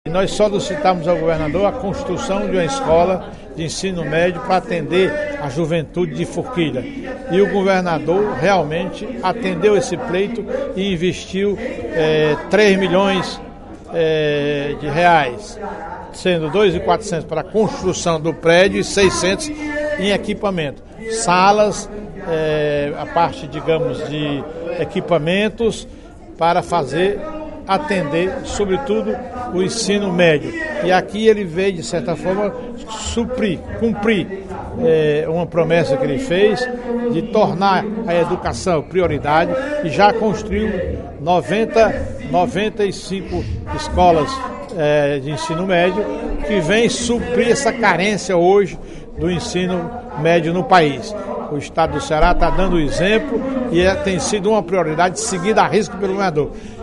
O deputado Professor Teodoro (PSD) comemorou, nesta sexta-feira (24/02) em sessão plenária, a inauguração da nova Escola Elza Goersch, que ocorrerá amanhã no município de Forquilha.